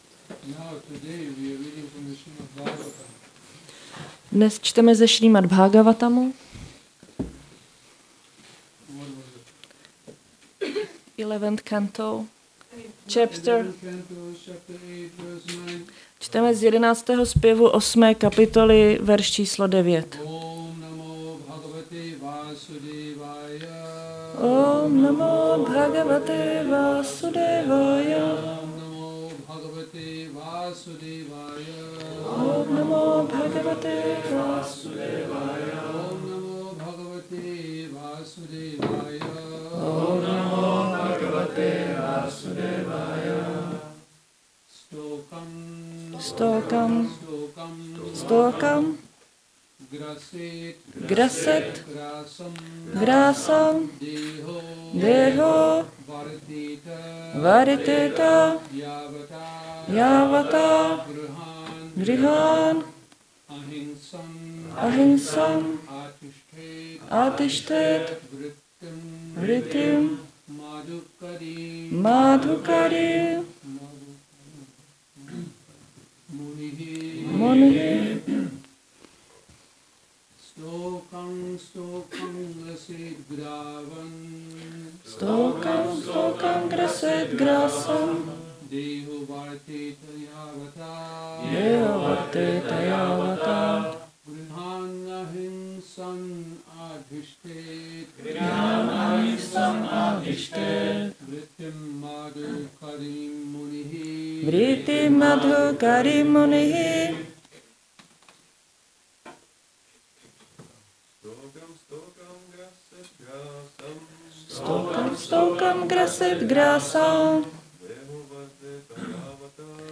Šrí Šrí Nitái Navadvípačandra mandir